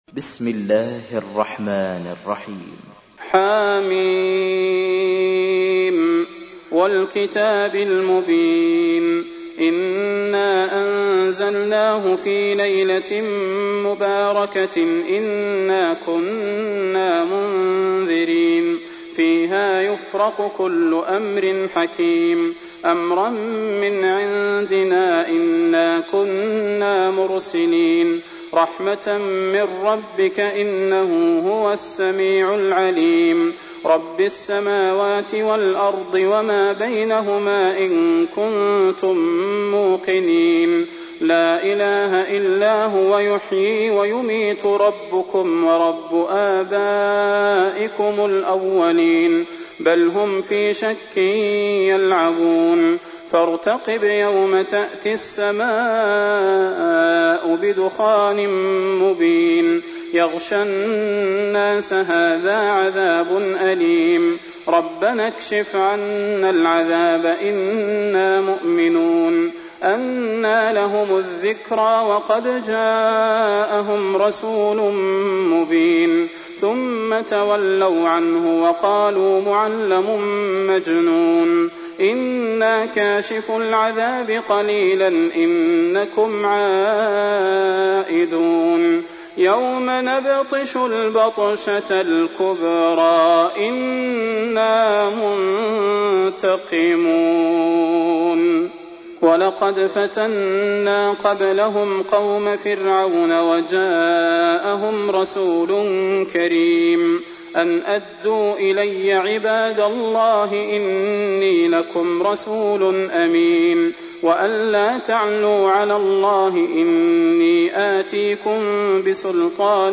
تحميل : 44. سورة الدخان / القارئ صلاح البدير / القرآن الكريم / موقع يا حسين